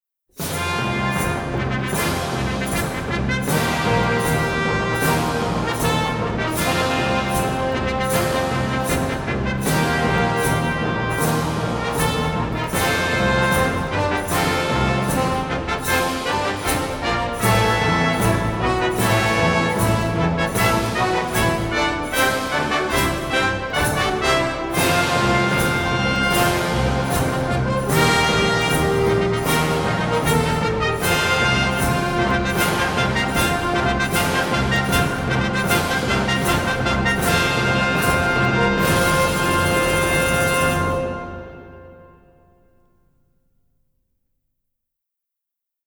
records and mixes at AIR Studios in London